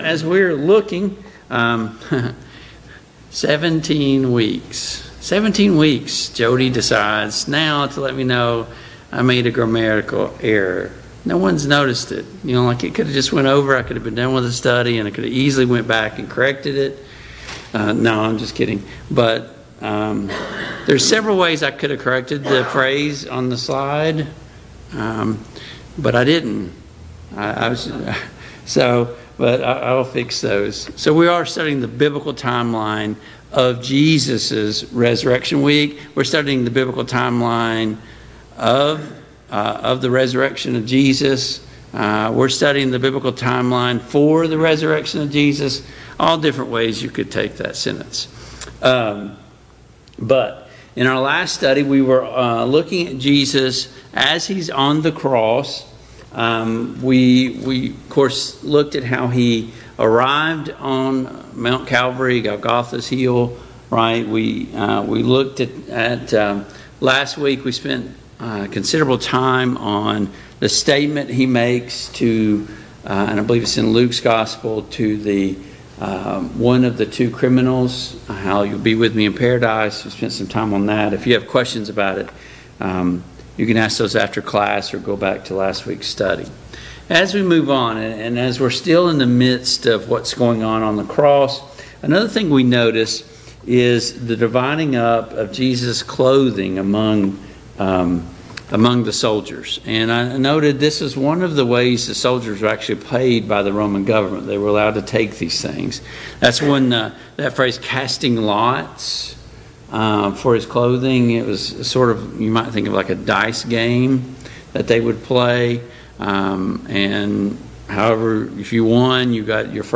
Biblical Timeline of Jesus' Resurrection Service Type: Mid-Week Bible Study Download Files Notes Topics: The Crucifixion , The Death of Jesus « 6.